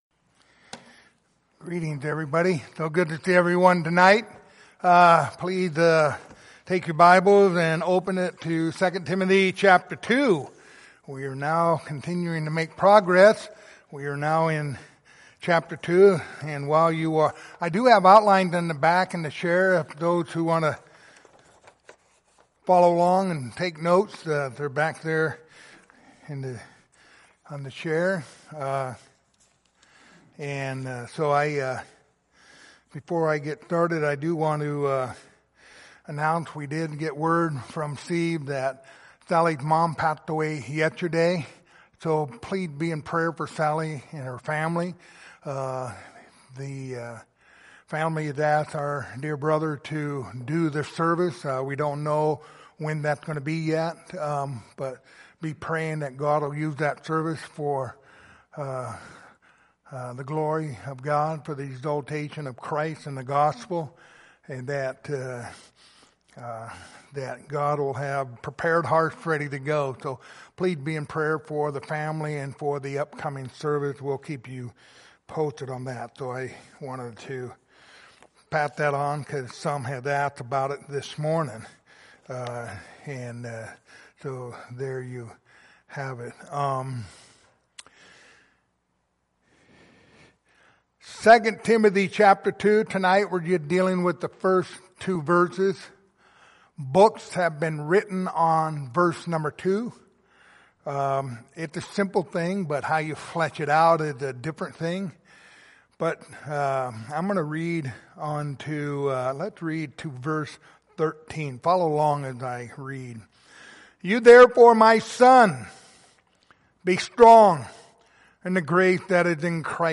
Passage: 2 Timothy 2:1-2 Service Type: Sunday Evening